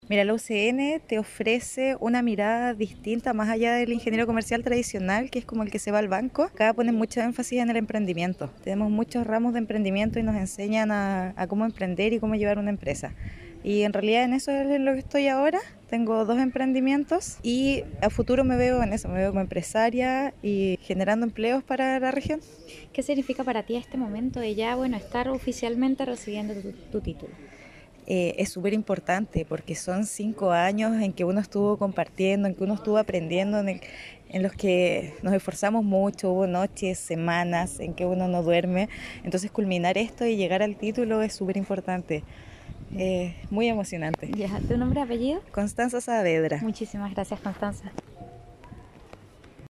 La actividad se llevó a cabo en el Auditorio del Campus Guayacán nuestra casa de estudios, hasta donde llegaron directivos, profesores, personal de la apoyo a la academia, y familiares para acompañar en tan importante momento a los graduandos.